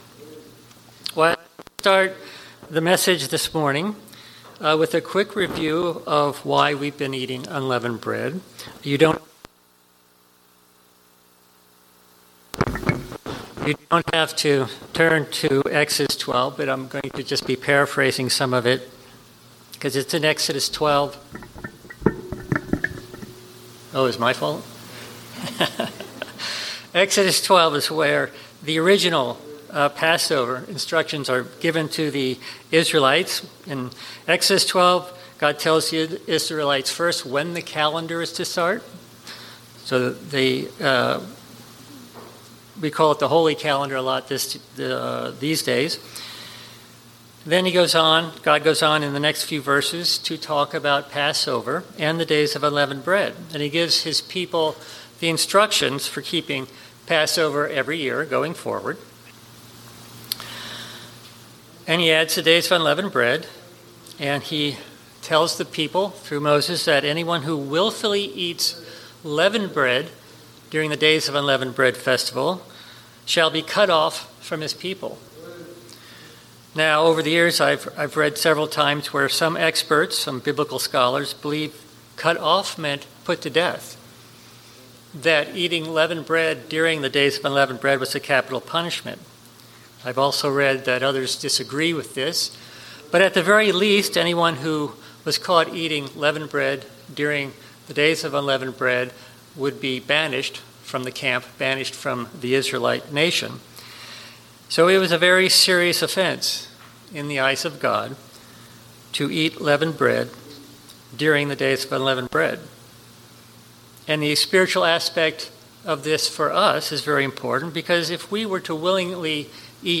This sermon explains that the Days of Unleavened Bread are a serious and meaningful observance that symbolize removing sin, remembering Christ’s sacrifice, and recognizing God’s deliverance of His people from both physical and spiritual affliction.
in the first minute or so of this sermon the microphone was running out of battery so it cuts out a little.